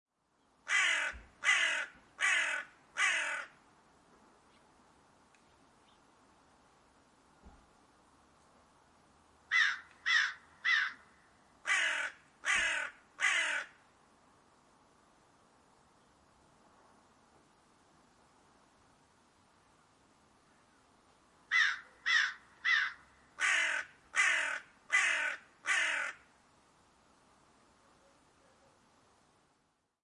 Krähen Ruf
• Ihr „Kräh-Kräh“-Ruf ist individuell – Artgenossen erkennen sich am Klang.
Kraehen-Ruf-Voegel-in-Europa.mp3